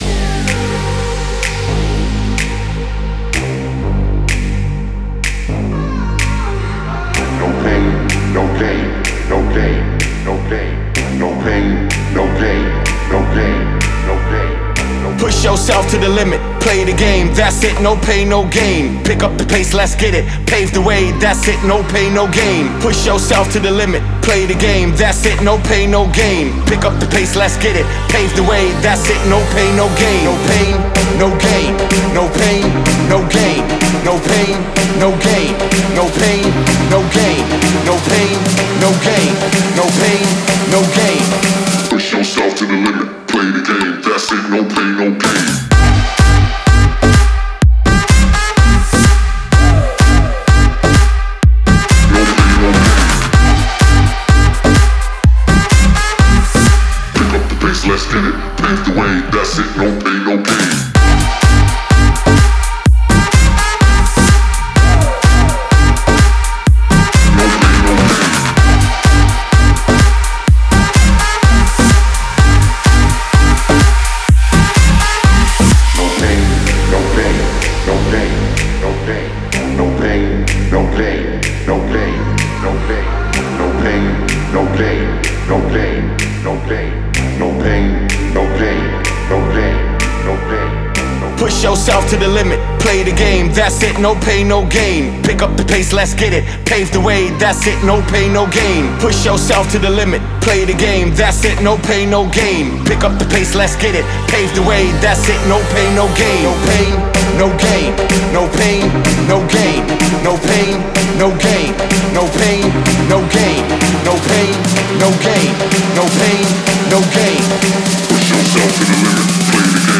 это энергичная трек